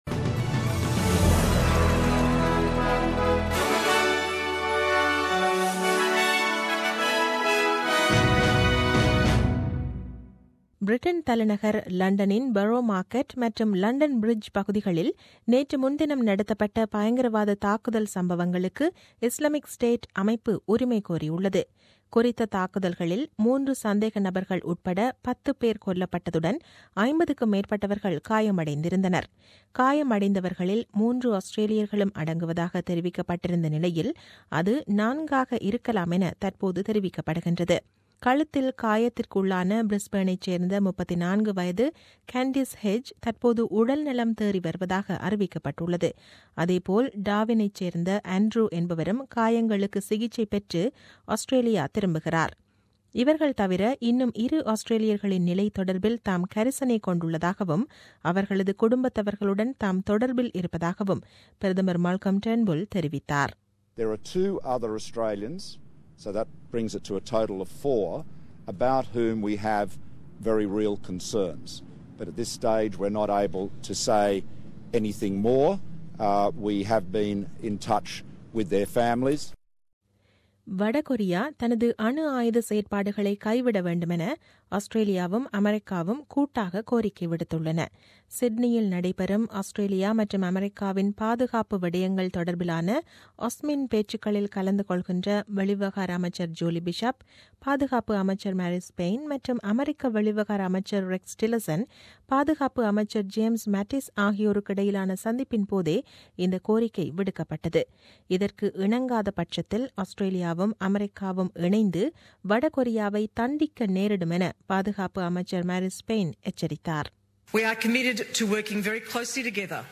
The news bulletin aired on 5 June 2017 at 8pm.